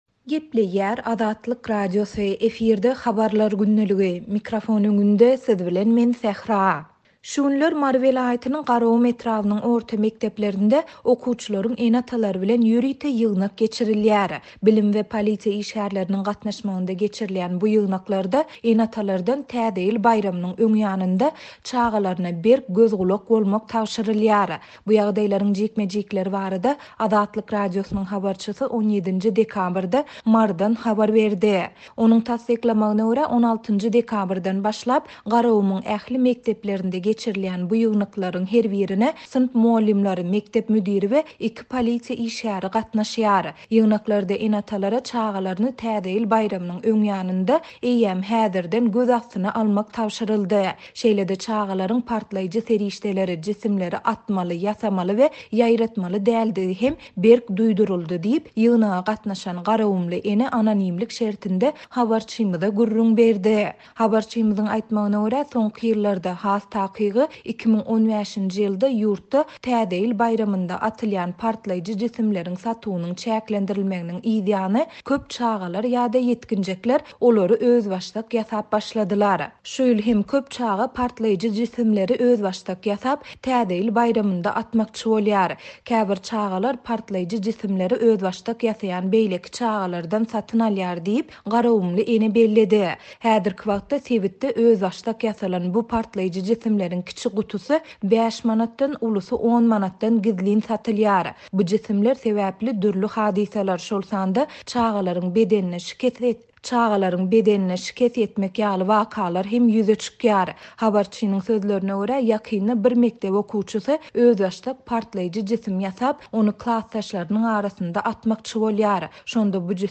Bu ýagdaýlaryň jikme-jikleri barada Azatlyk Radiosynyň habarçysy 17-nji dekabrda Marydan habar berdi.